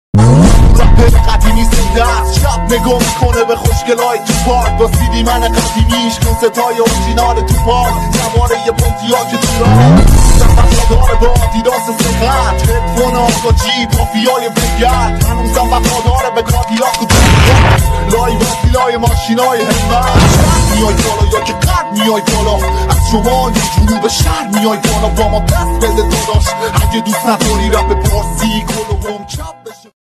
ریمیکس بیس دار